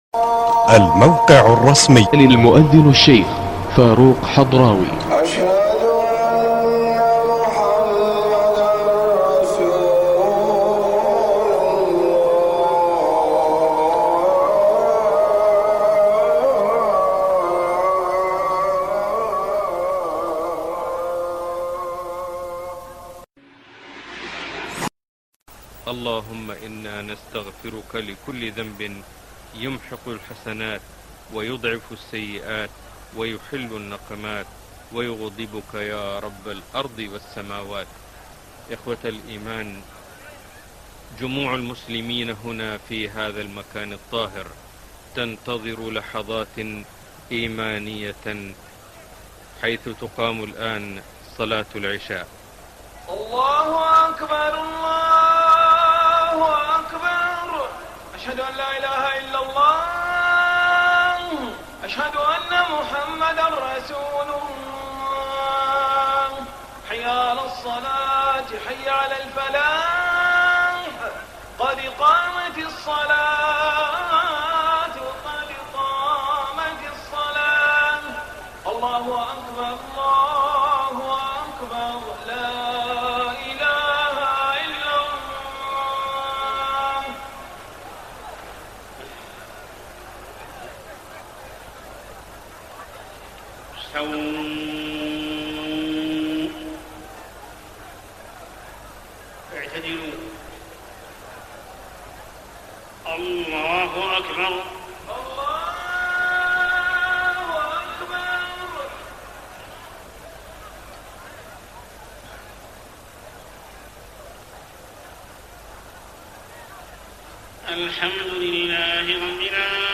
صلاة العشاء 26 رمضان 1423هـ سورتي الضحى و الشرح > 1423 🕋 > الفروض - تلاوات الحرمين